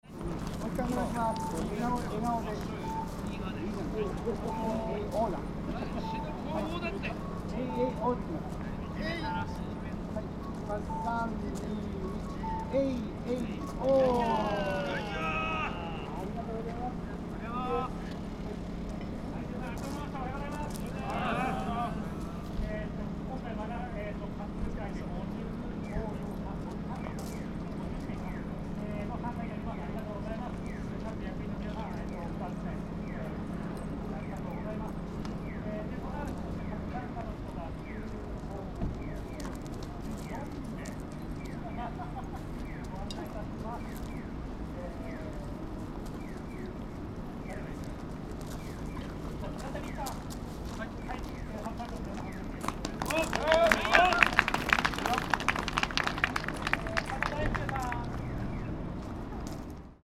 The station street had been closed to traffic, and the big Japanese sandal was displayed. ♦ When I passed through the street, sandal bearers were taking a commemorative photo before departure.